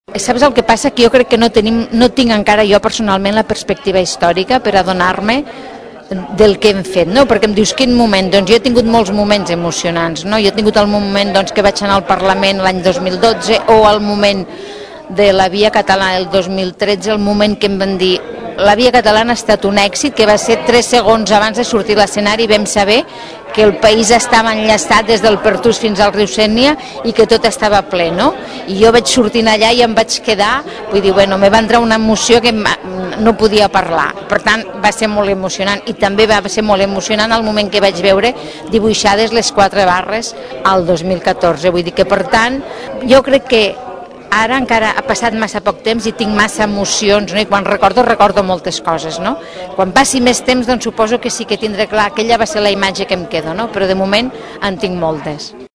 Al micròfon de Ràdio Tordera, Forcadell valorava aquesta etapa de molt positiva.